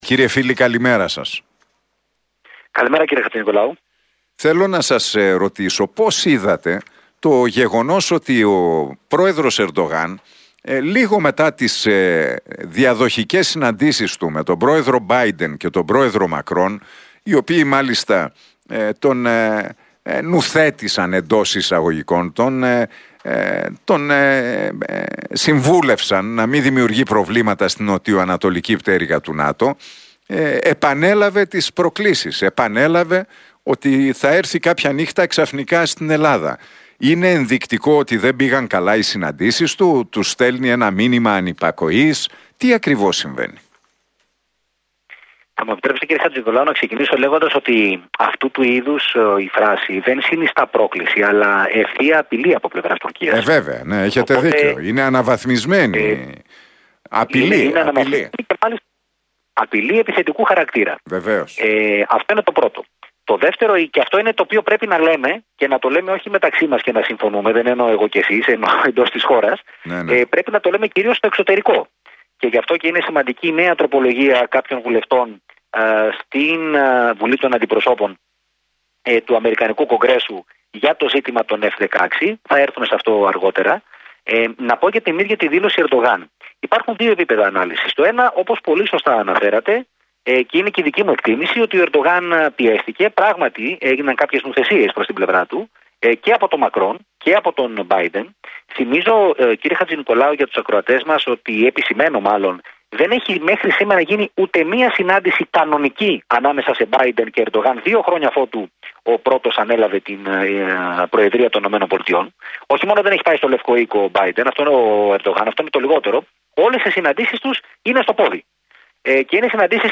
ο αναλυτής διεθνών θεμάτων
μιλώντας στον Realfm 97,8 και στην εκπομπή του Νίκου Χατζηνικολάου.